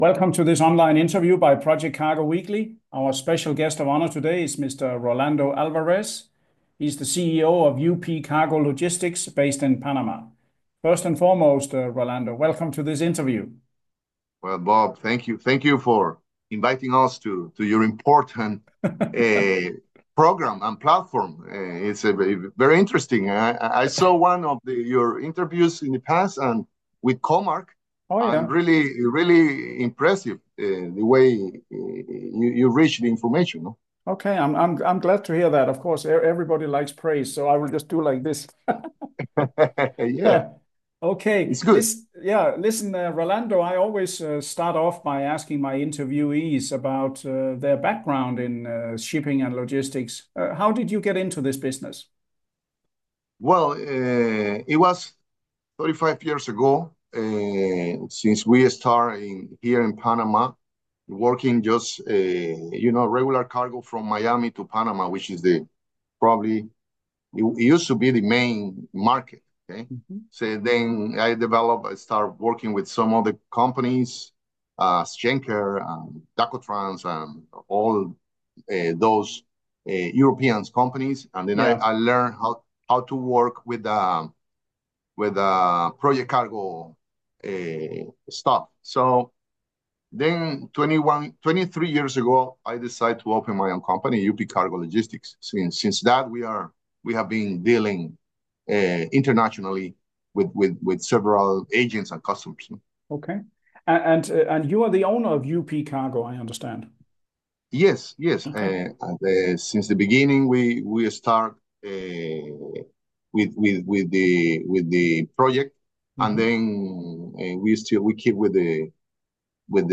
Video InterviewUPCargo Logistics Panama